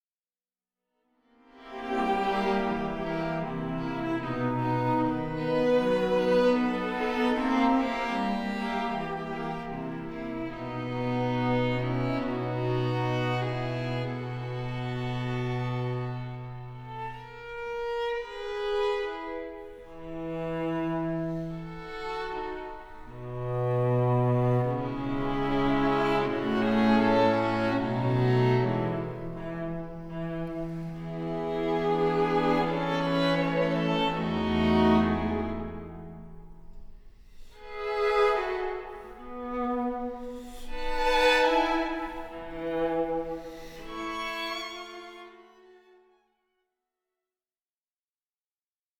Grave (1.29 EUR)